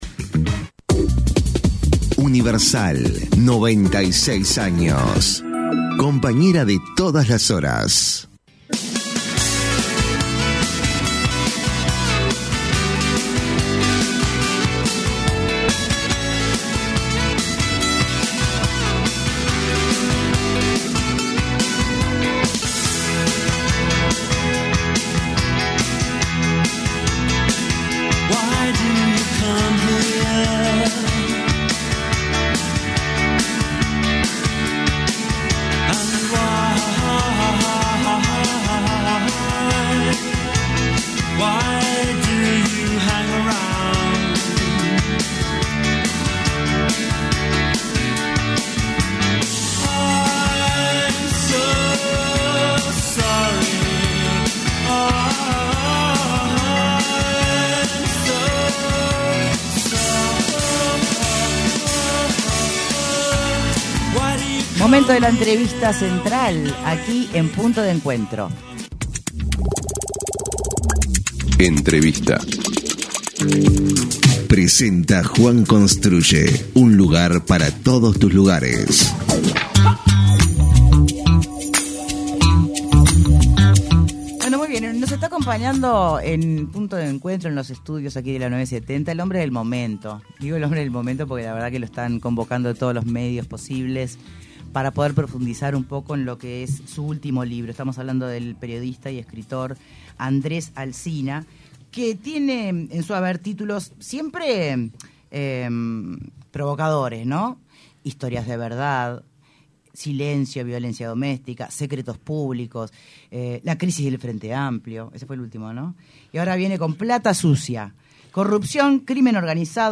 entrevista con Punto de Encuentro